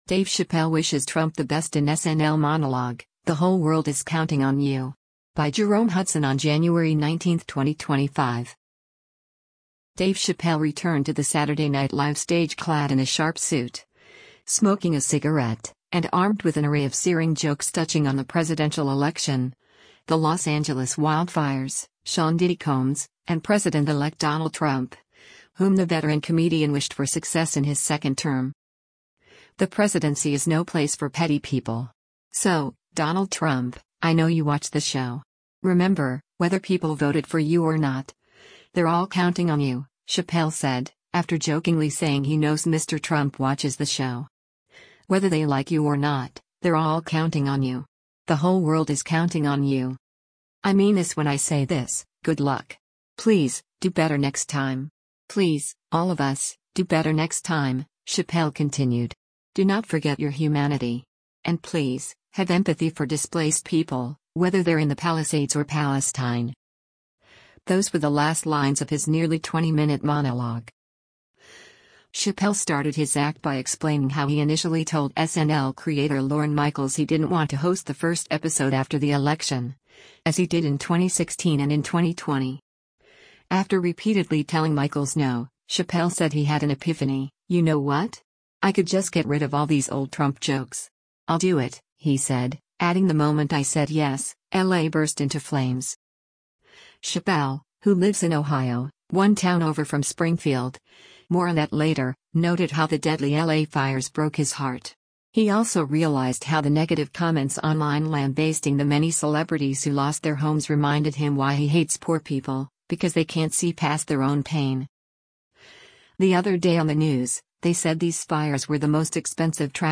Dave Chappelle Wishes Trump the Best in ‘SNL’ Monologue: 'The Whole World Is Counting on You'